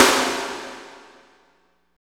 48.10 SNR.wav